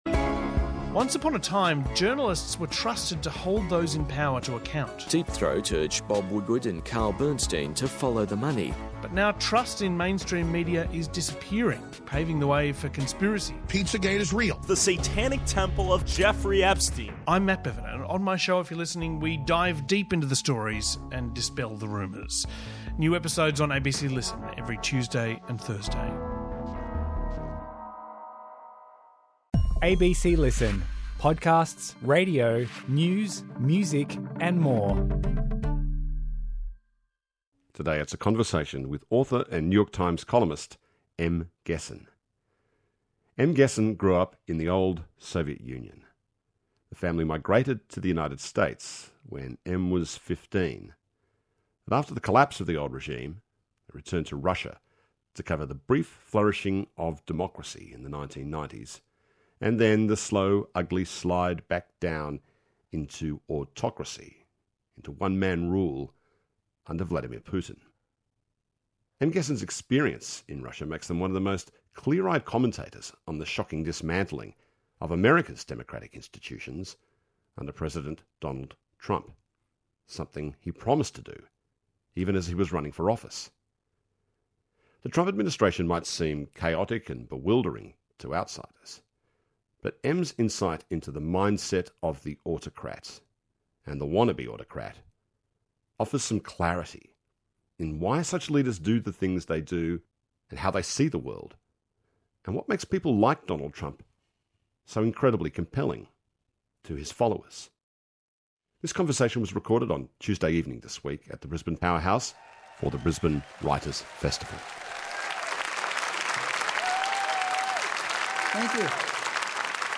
This Conversation was recorded at the Brisbane Powerhouse, as part of the Brisbane Writers Festival.